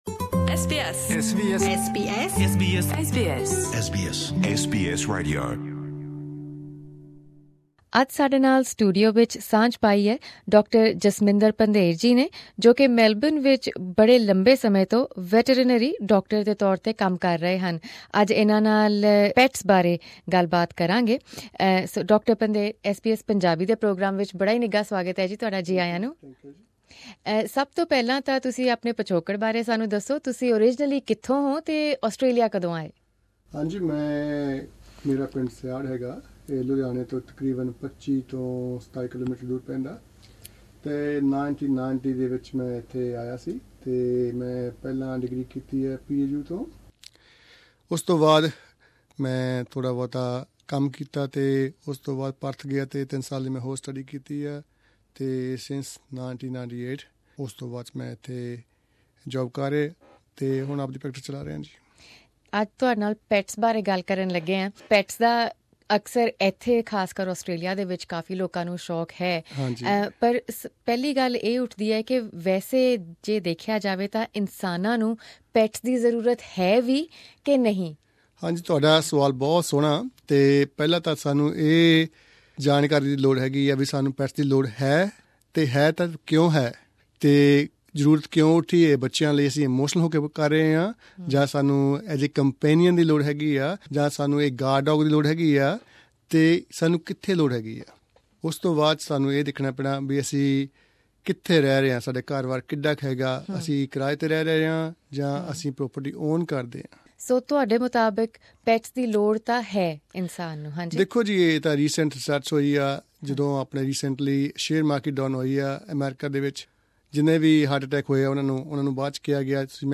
SBS Melbourne Studios
interview